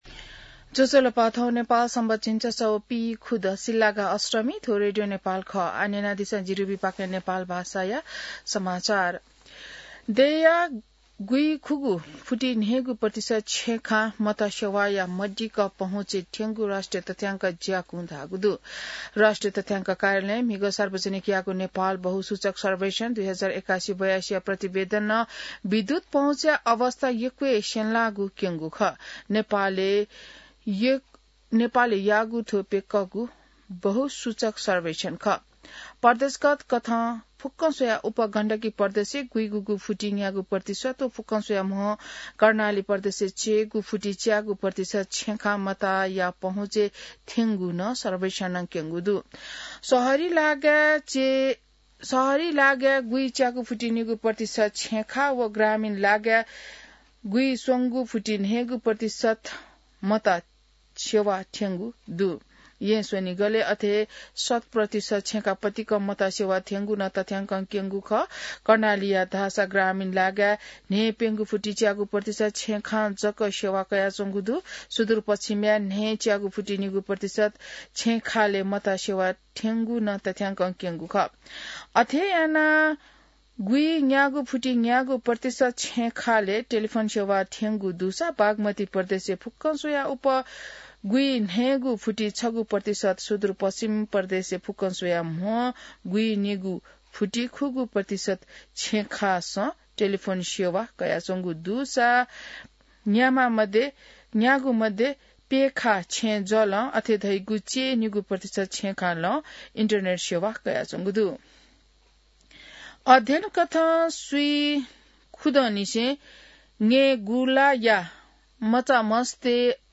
नेपाल भाषामा समाचार : २७ माघ , २०८२